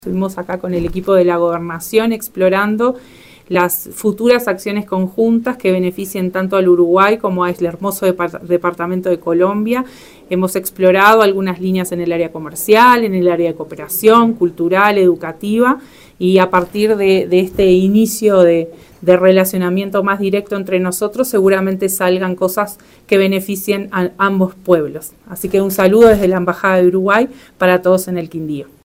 _Nota_de_voz_ministra_consejera_Maria_Lourdes_Bone_Dadalt.mp3